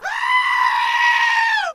Goat Scream